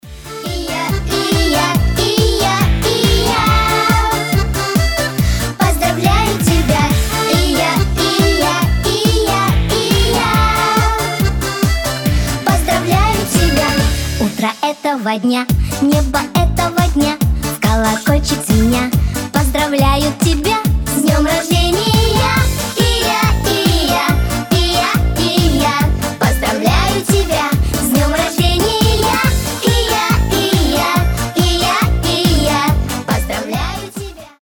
Веселые рингтоны
Поп